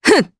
Valance-Vox_Attack1_jp.wav